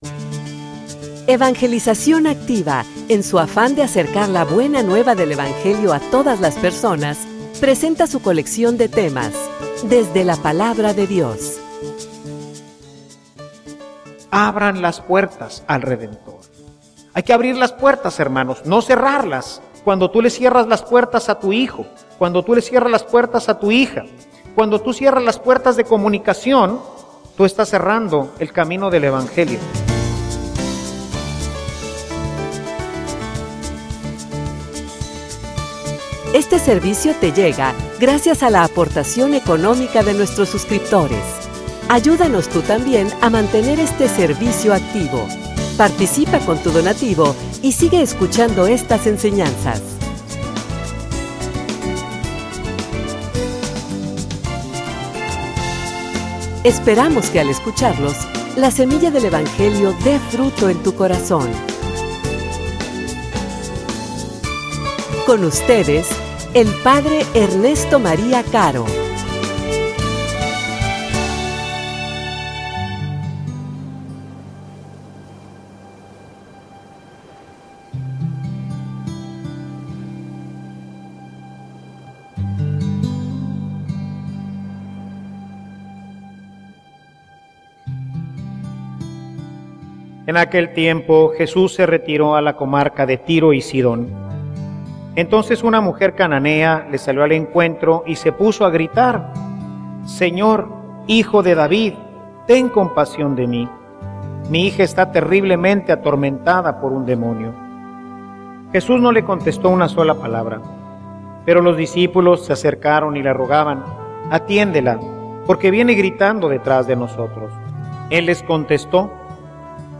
homilia_Un_amor_que_nos_distingue_y_une.mp3